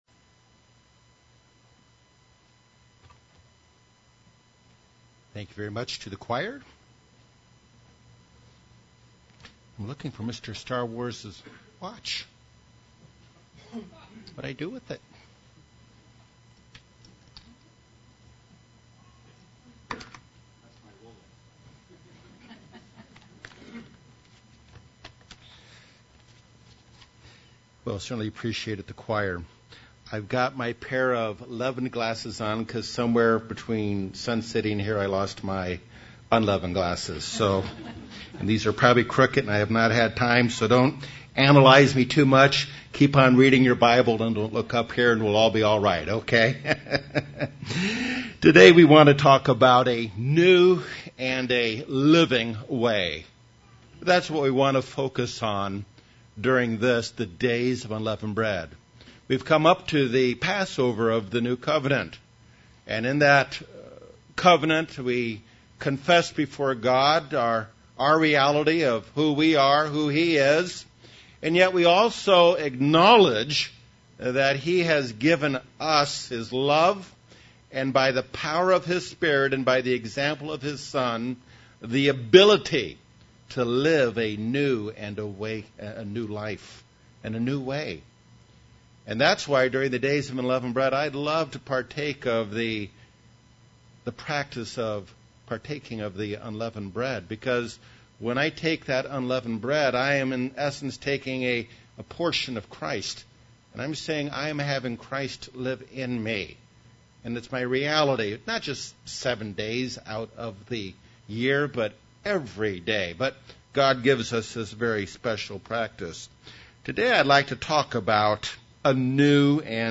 Given on the First Day of Unleavened Bread.